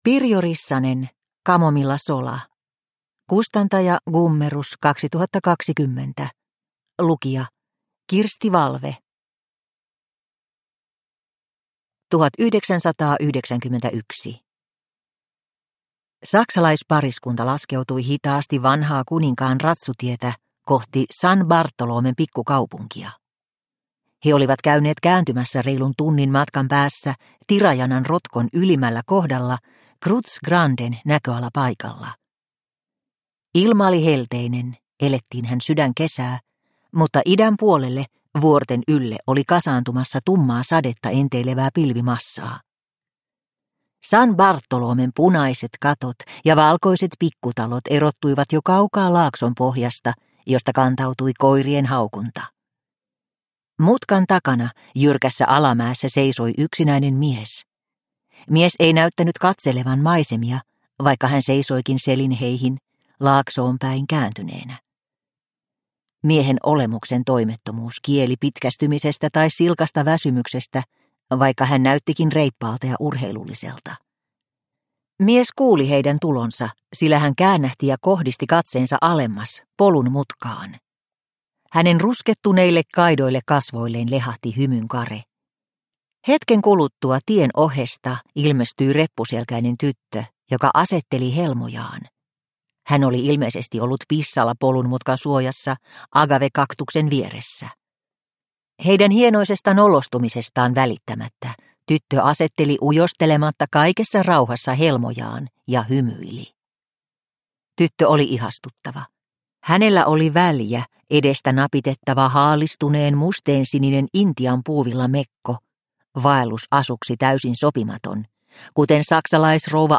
Kamomillasola – Ljudbok – Laddas ner